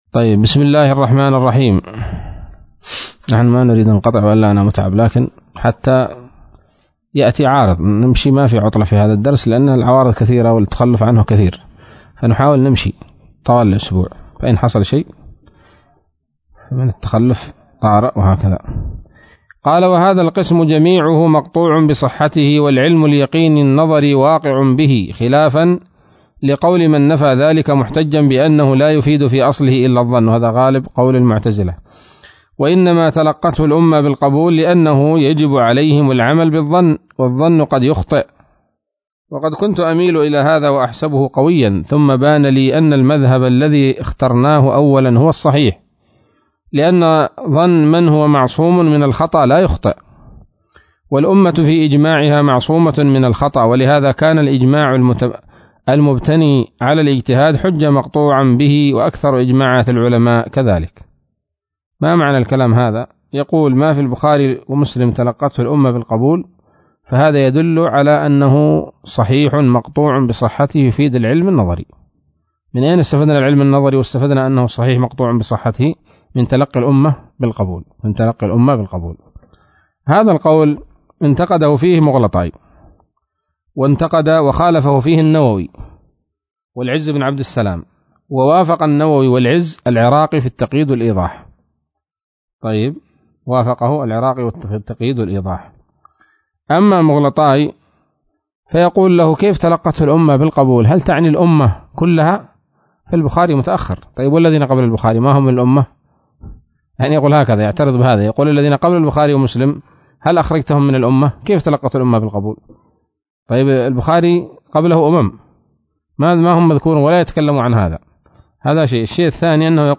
الدرس الثاني عشر من مقدمة ابن الصلاح رحمه الله تعالى